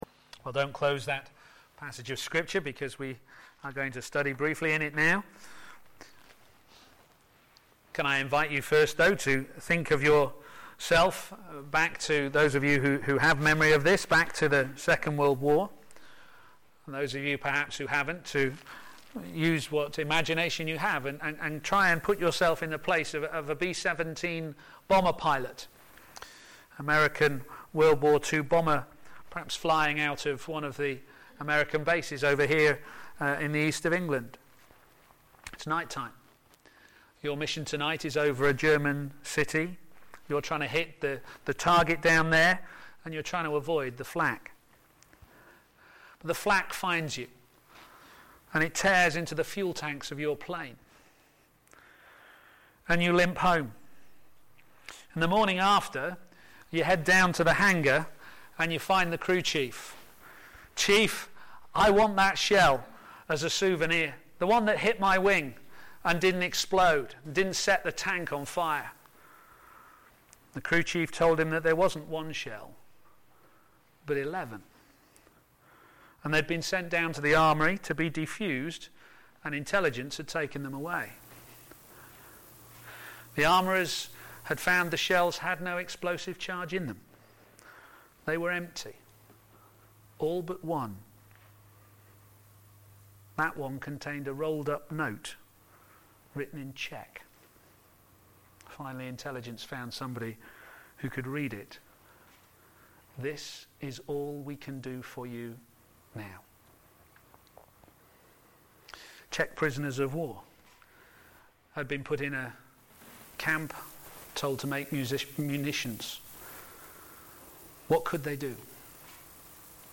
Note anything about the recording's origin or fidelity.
Media for p.m. Service